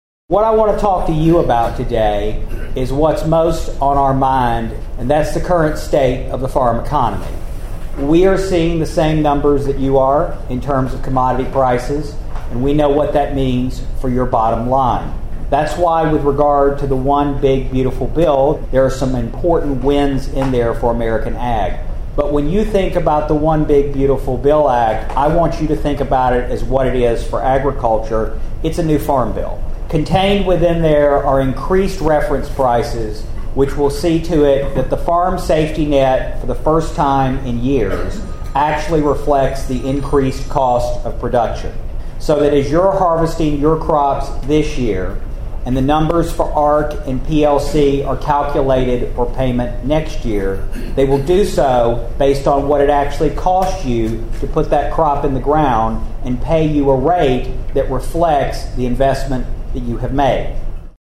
The Obion County Fair hosted their annual Farmers Harvest Breakfast on Thursday morning.
The featured guest speaker at the breakfast was Obion County’s Stephen Vaden, who is now the United States Deputy Secretary of Agriculture.